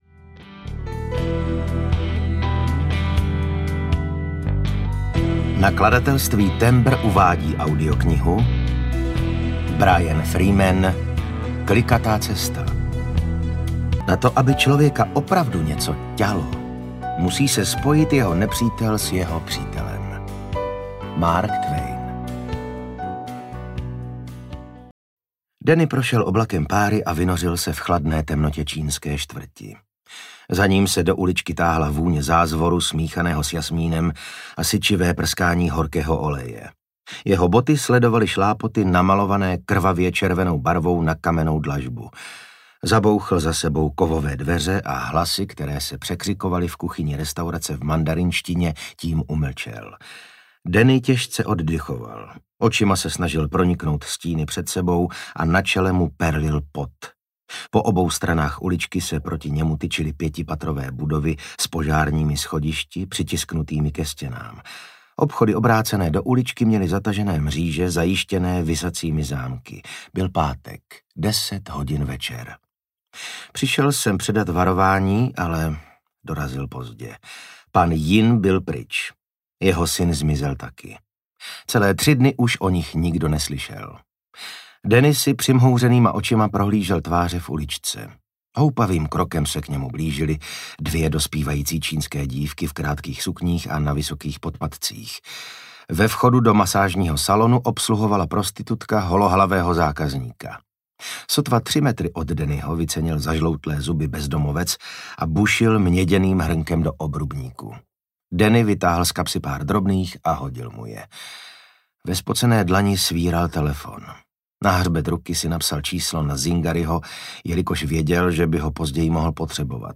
Klikatá cesta audiokniha
Ukázka z knihy
• InterpretVasil Fridrich